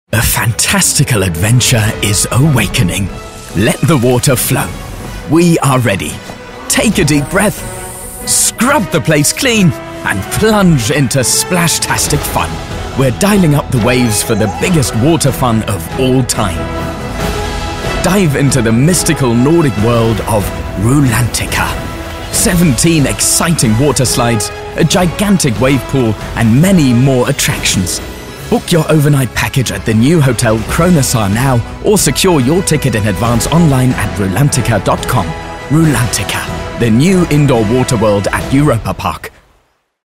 Seine Stimme ist warm, jung, dynamisch, authentisch, natürlich.
Sprechprobe: Sonstiges (Muttersprache):
His voice is warm, young, upbeat, credible, natural.
ENGLISCH - Theme Park - Rulantica Water World.mp3